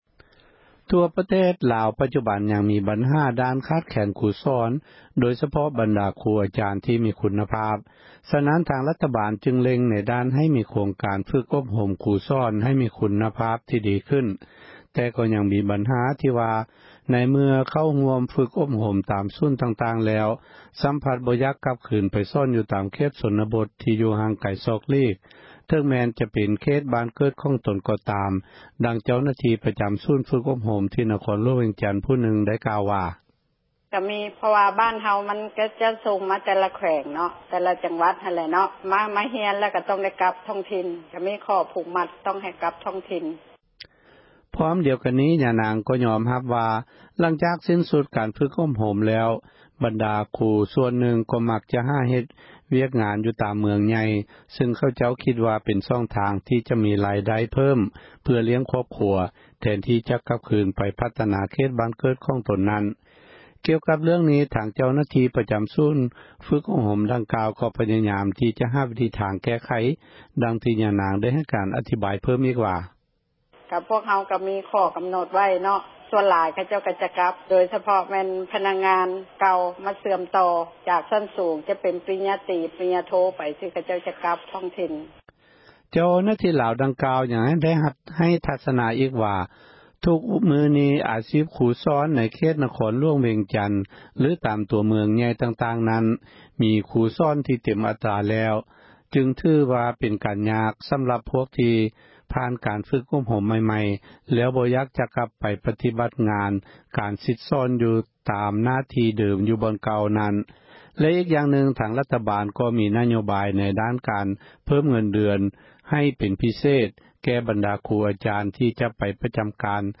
ຣາຍງານ